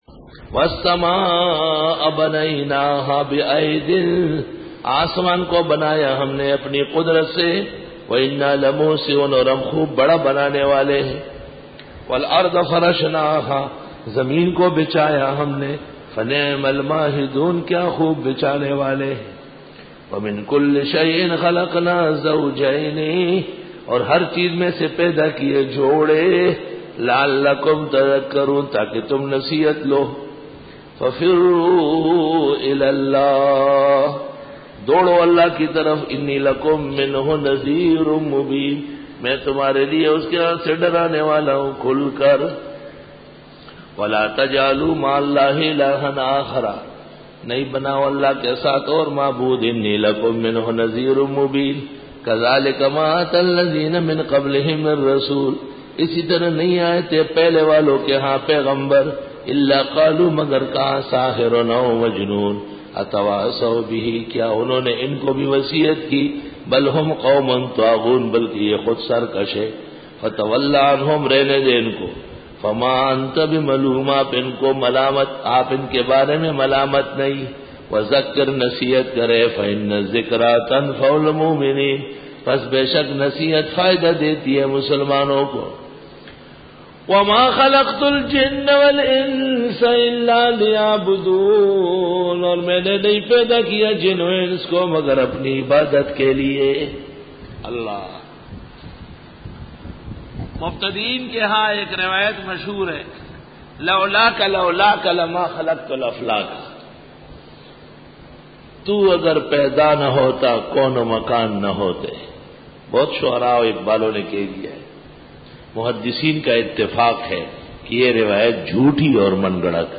Dora-e-Tafseer